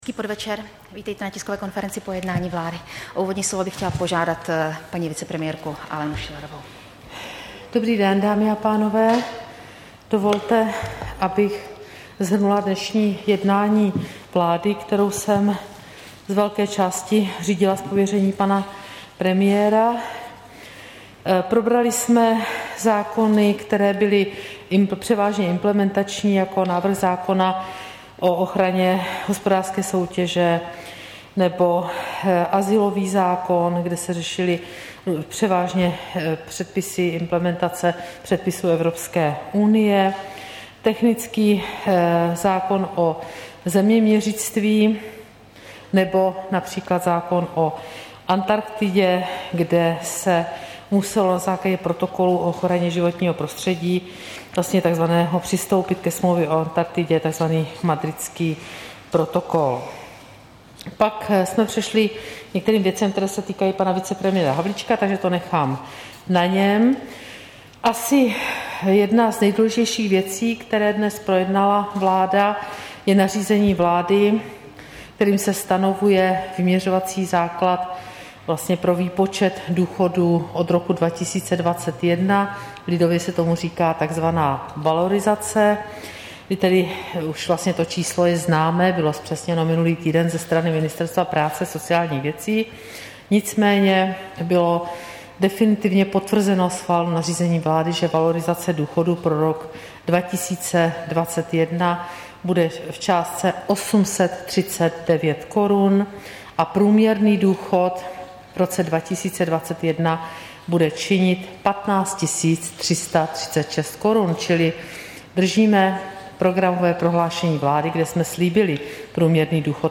Tisková konference po jednání vlády, 21. září 2020